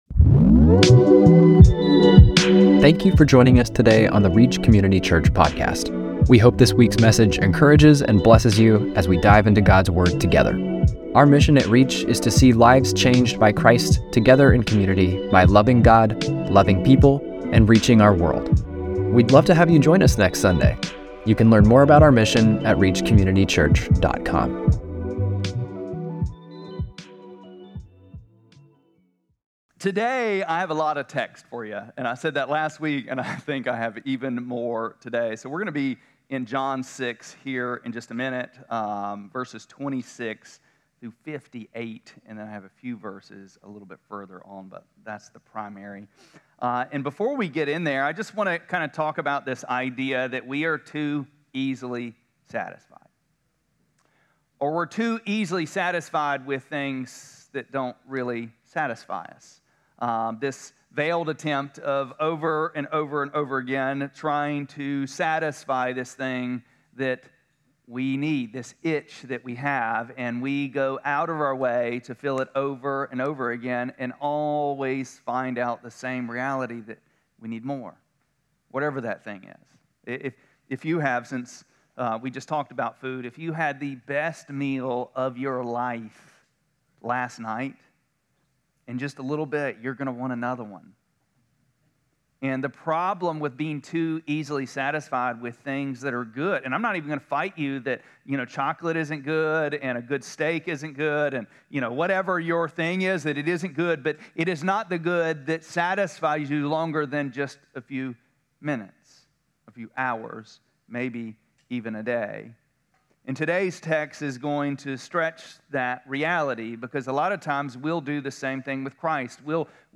5-18-25-Sermon.mp3